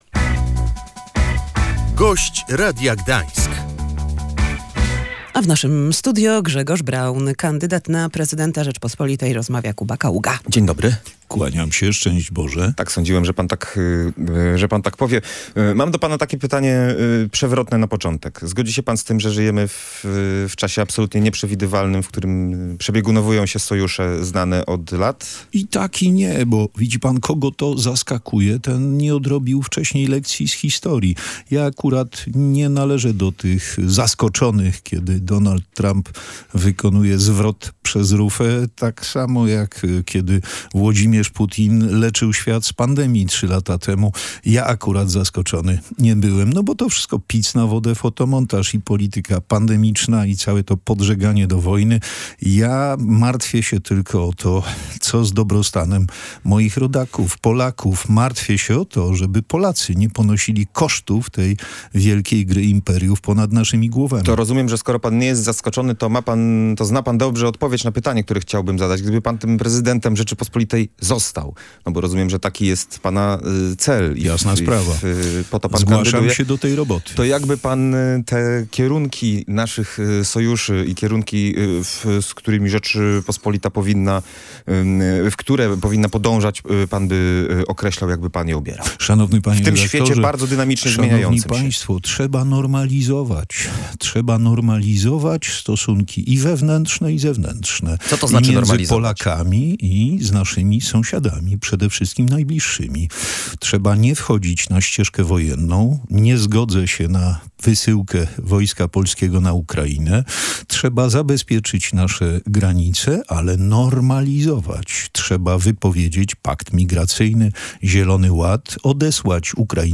Współpraca z Białorusią pomogłaby obniżyć rachunki za prąd – mówił w Radiu Gdańsk Grzegorz Braun, kandydat na prezydenta Polski.